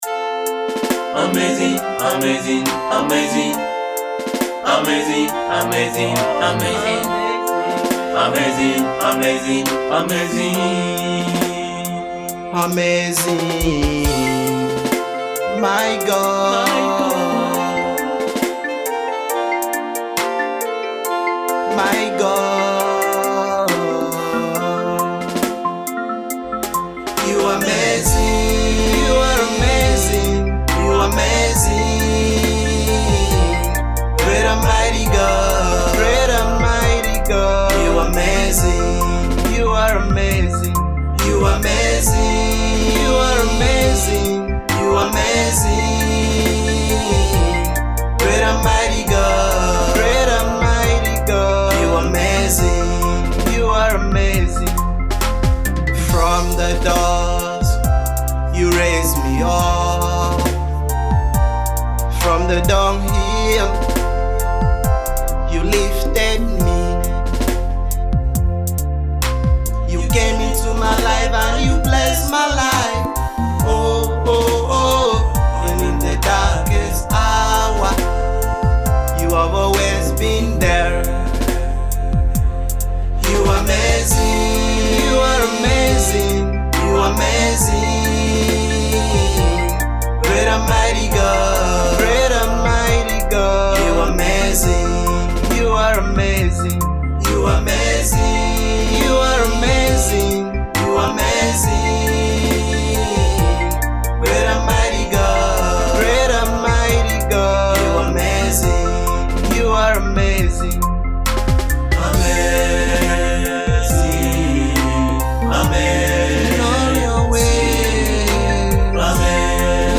Gospel singer